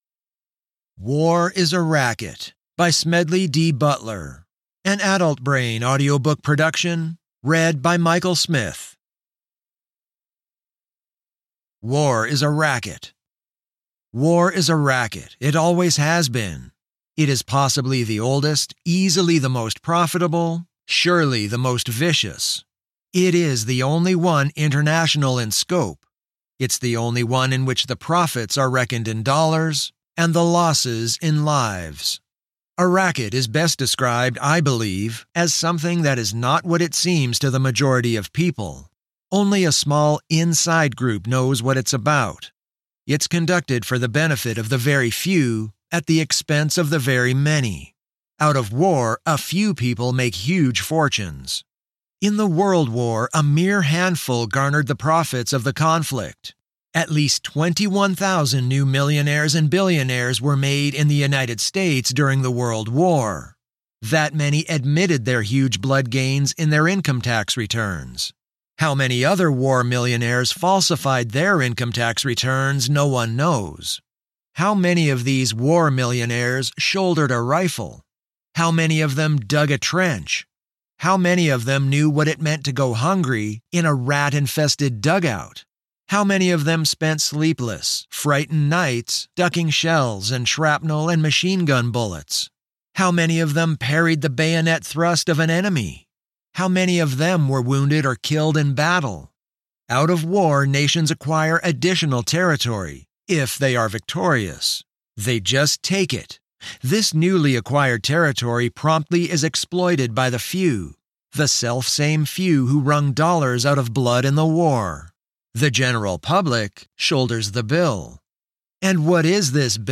In this concise but thought-provoking audiobook, Butler argues that war has become a “racket” — a business that enriches corporations, bankers, and industrialists while ordinary citizens and soldiers bear the costs in blood, money, and loss. Through clear language and sharp insight, he explores why wars are fought, who makes the profits, and who pays the bills, and he even lays out practical steps to dismantle the war racket.